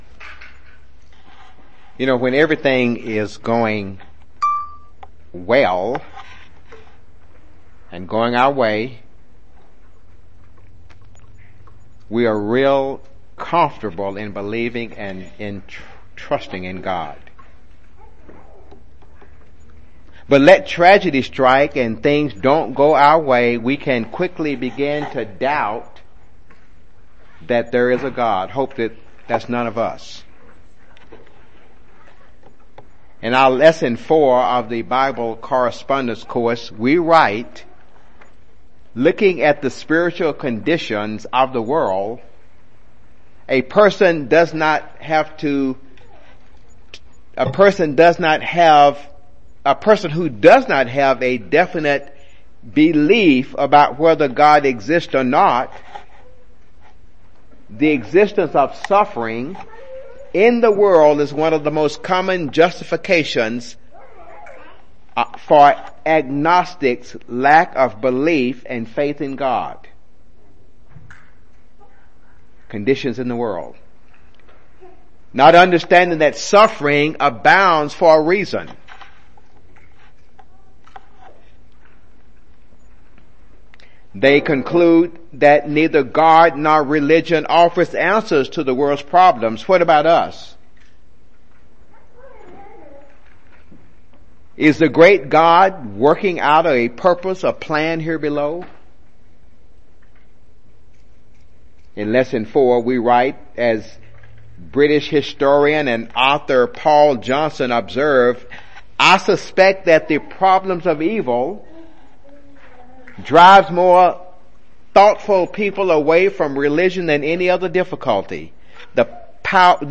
Given in Jackson, TN
UCG Sermon Studying the bible?